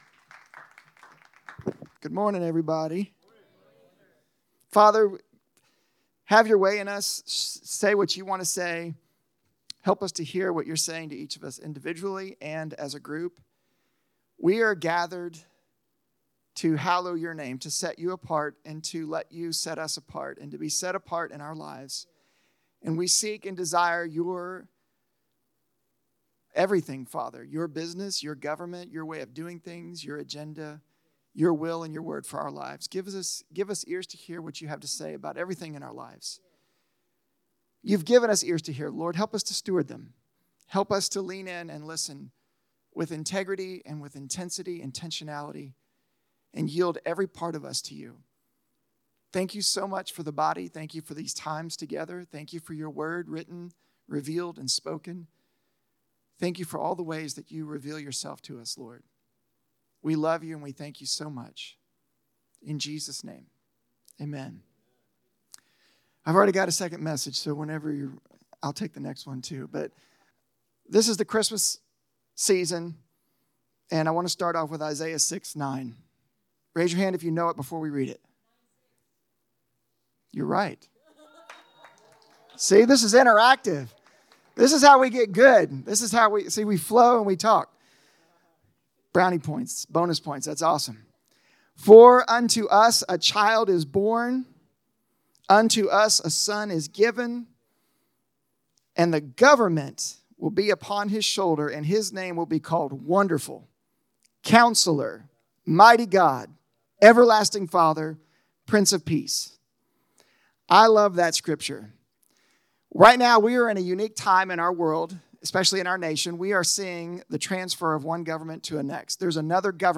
The Mystery of Manna – SERMONS